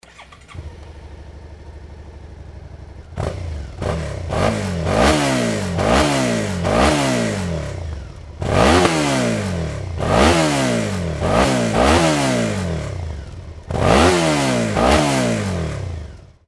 650ccのV型2気筒エンジンという事で
空ふかしの排気音
2気筒エンジンらしい音質になる感じですね。